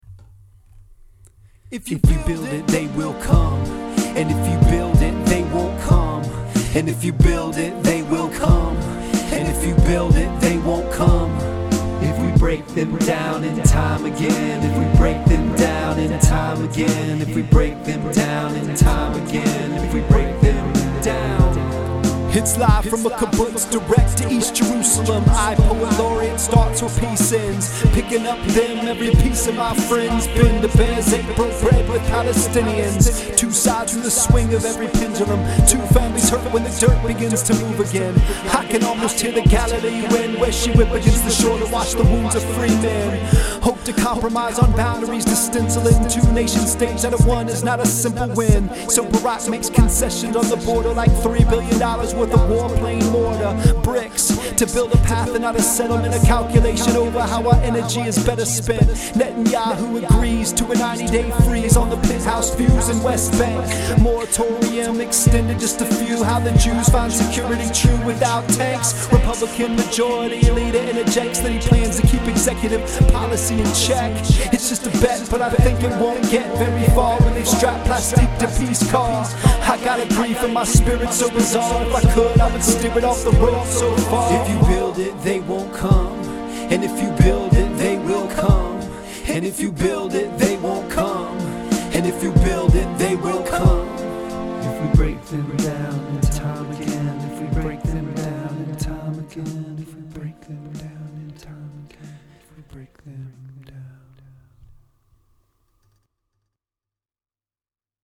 Today’s sultry audio here: